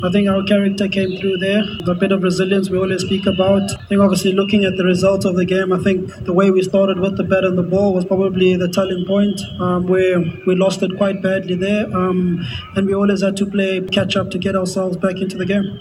Die Protea-kaptein, Temba Bavuma, sê hy is trots op hoe die span tot die einde geveg het: